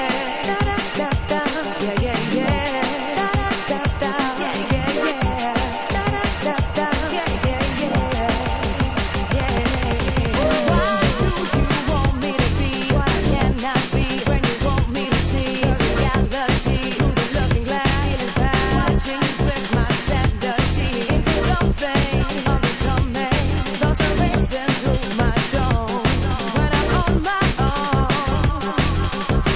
TOP > Deep / Liquid